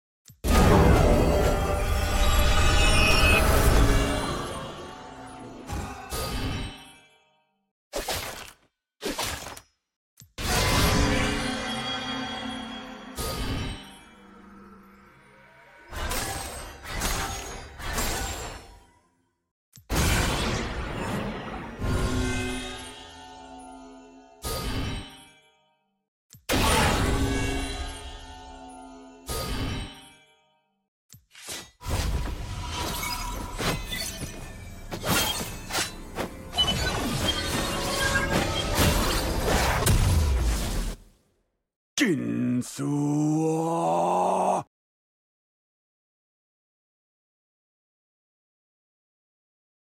💥 Ult Sounds, Voice, & More 💥